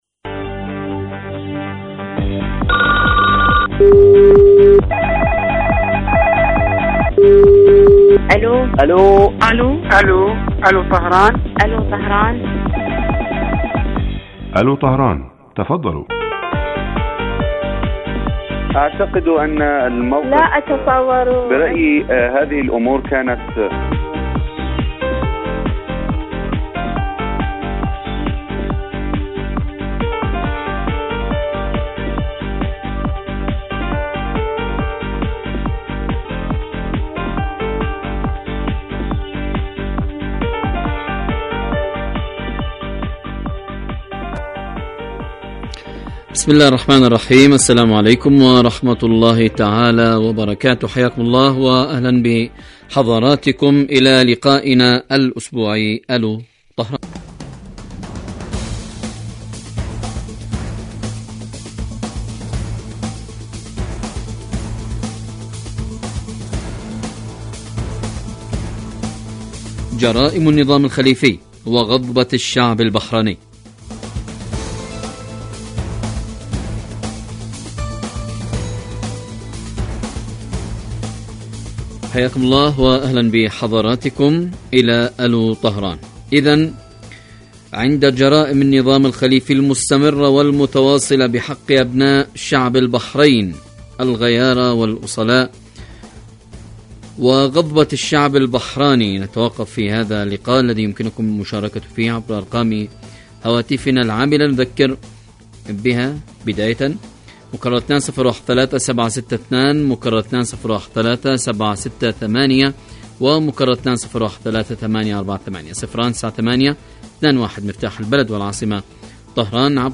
برنامج حي يهدف إلى تنمية الوعي السياسي من خلال طرح إحدى قضايا الساعة الإيرانية والعالمية حيث يتولى مقدم البرنامج دور خبير البرنامج أيضا ويستهل البرنامج بمقدمة يطرح من خلال محور الموضوع على المستمعين لمناقشته عبر مداخلاتهم الهاتفية.
يبث هذا البرنامج على الهواء مباشرة مساء أيام الجمعة وعلى مدى ثلاثين دقيقة